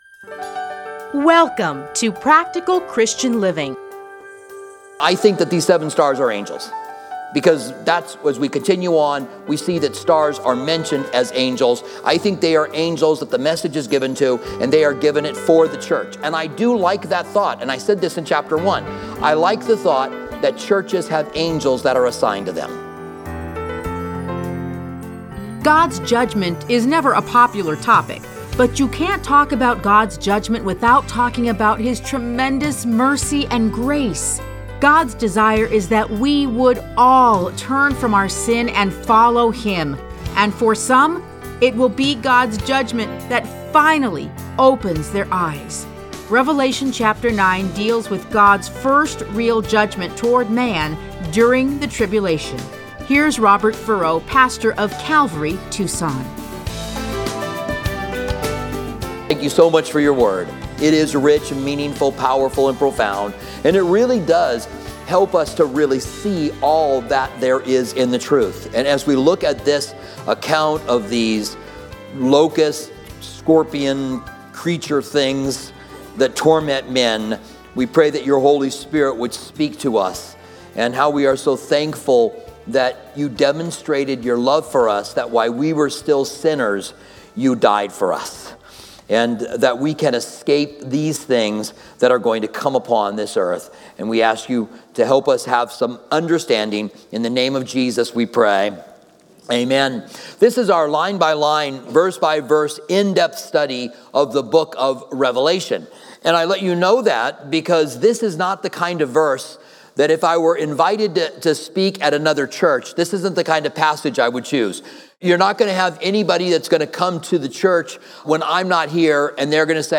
Listen to a teaching from Revelation 9:1-12.